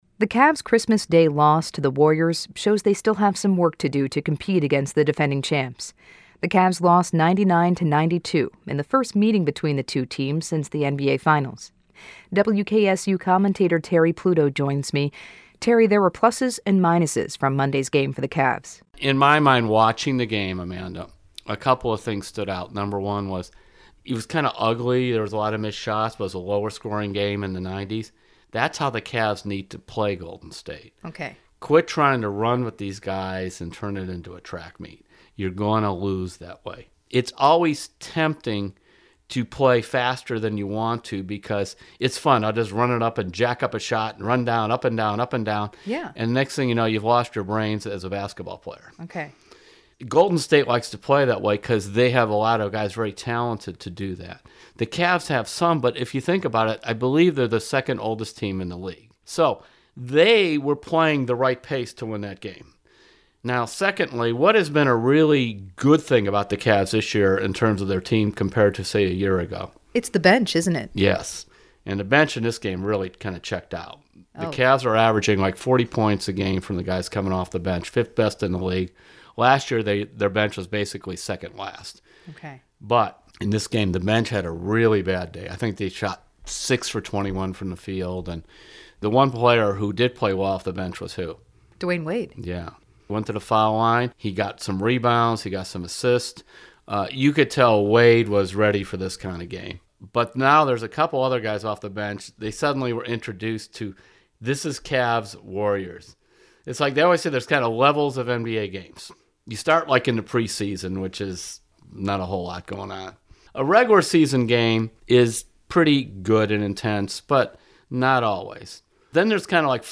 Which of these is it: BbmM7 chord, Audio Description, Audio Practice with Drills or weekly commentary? weekly commentary